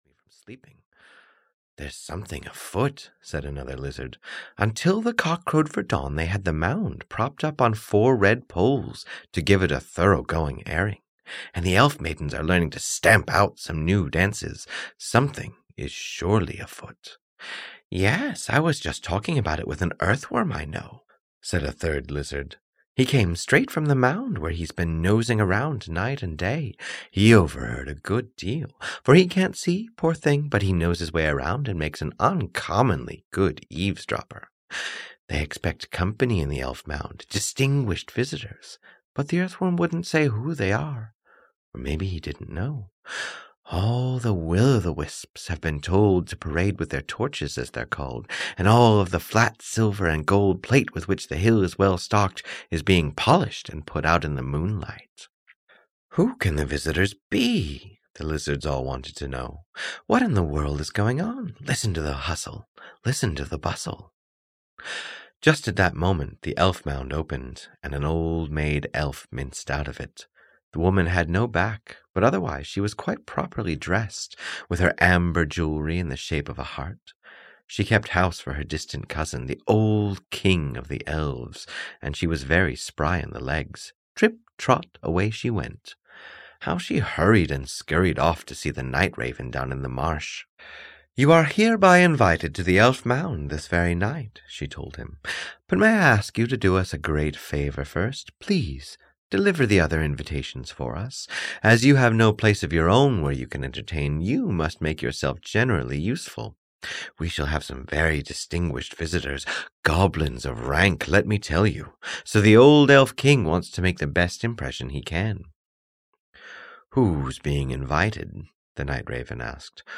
The Elf Mound (EN) audiokniha